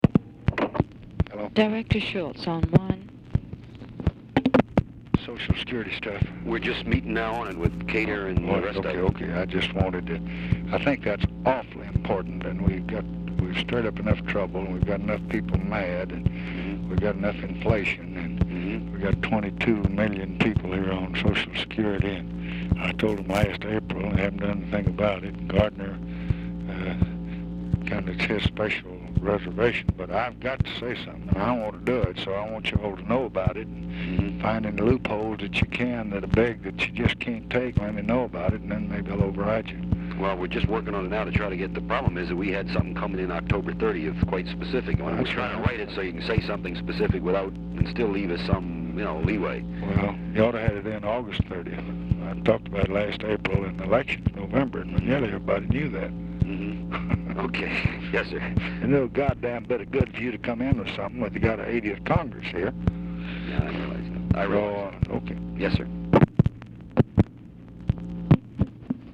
RECORDING STARTS AFTER CONVERSATION HAS BEGUN
Format Dictation belt
Location Of Speaker 1 Oval Office or unknown location
Specific Item Type Telephone conversation Subject Economics Elections Lbj Speeches And Statements Welfare And War On Poverty